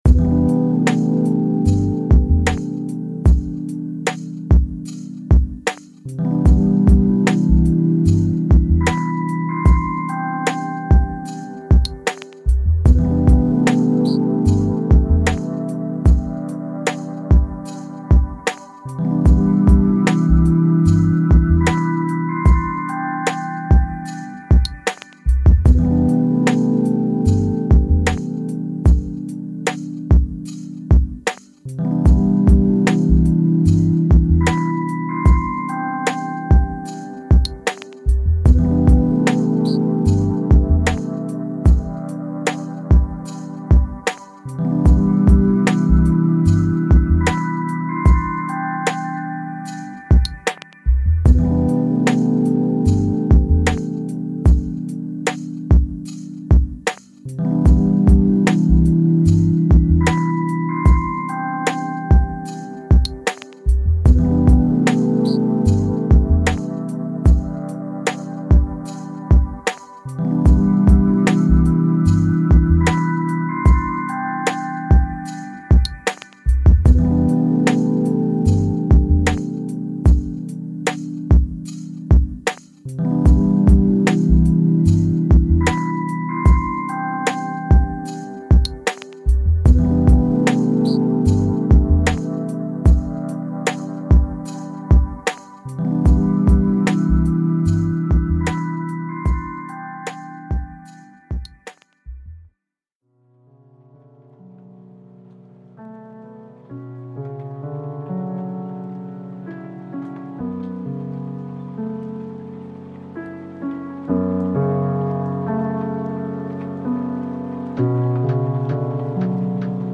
Chakra Plexus : 364 Hz Force